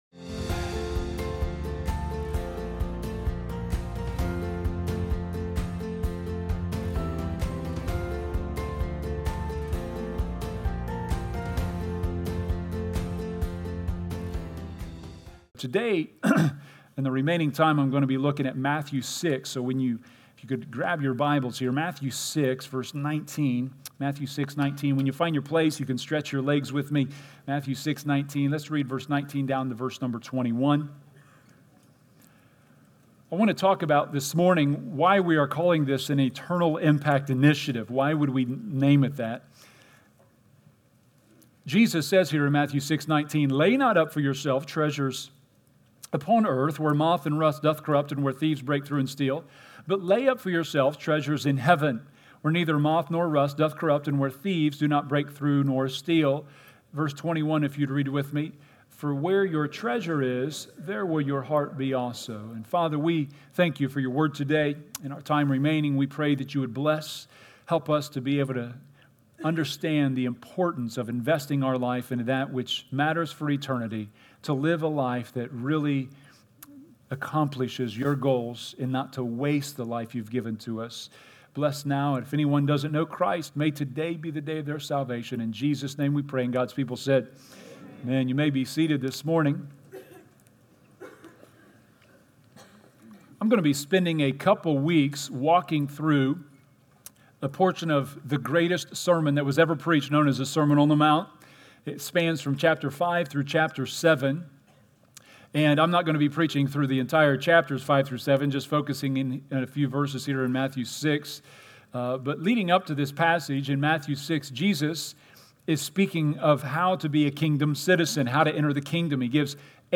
Message